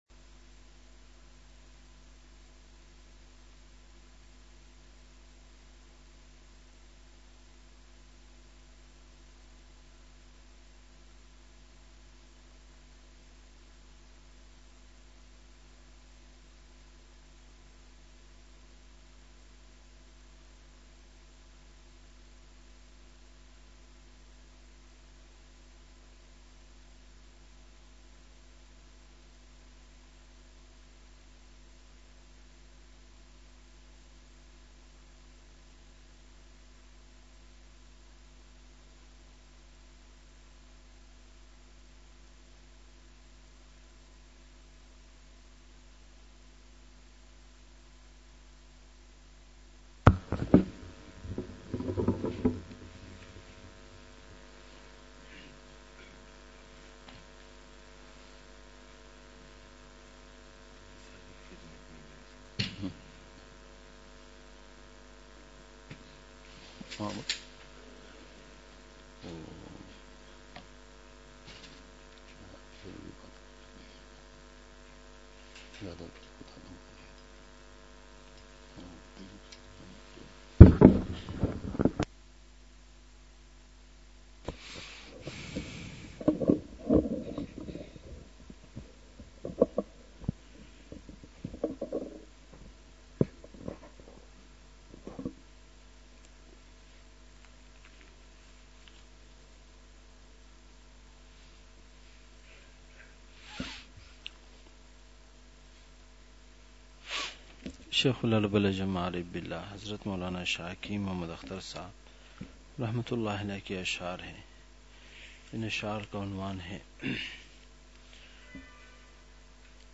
عشاء مجلس ۲۱ جنوری ۲۶ء: تقویٰ، صحبتِ صالحین،اصلاحِ نفس !
*مقام:مسجد اختر نزد سندھ بلوچ سوسائٹی گلستانِ جوہر کراچی*